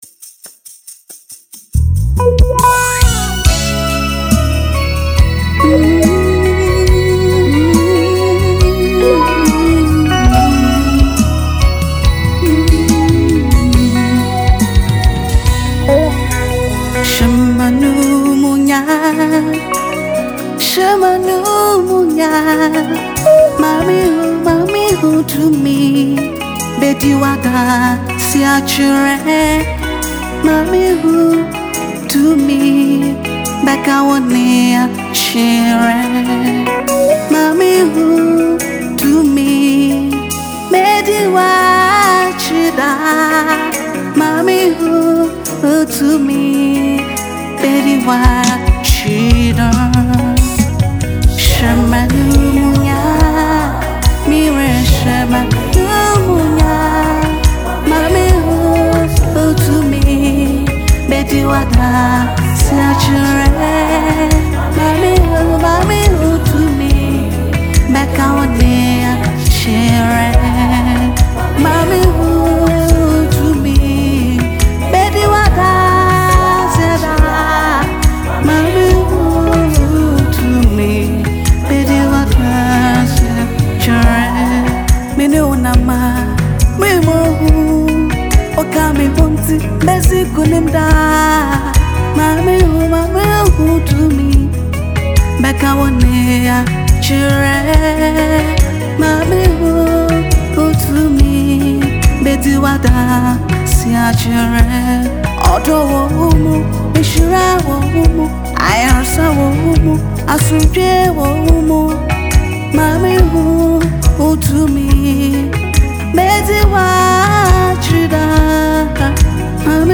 Ghanaian Gospel singer
In this powerful song
As a fast-rising Gospel musician
captivating melodies